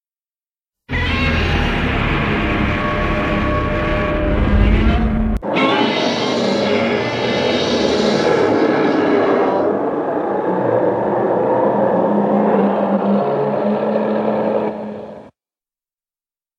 PLAY Screec.
screec.mp3